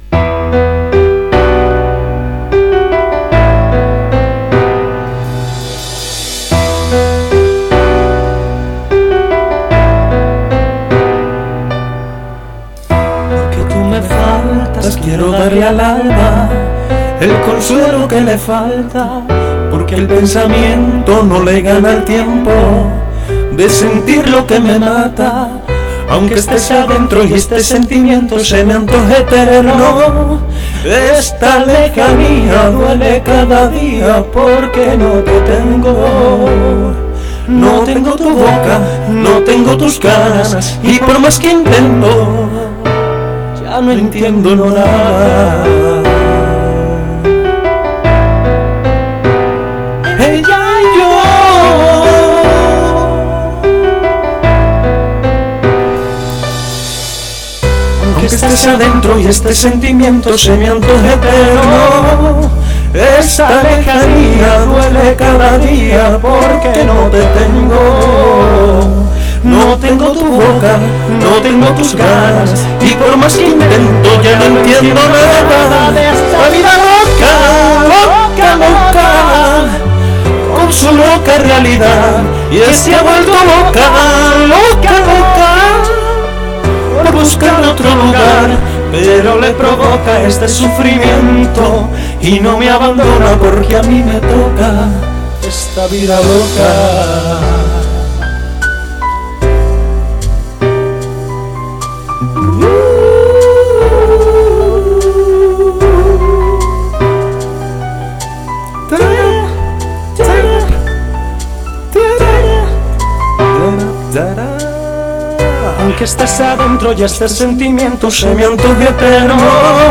Me gusta cantar, pero sólo como aficionado.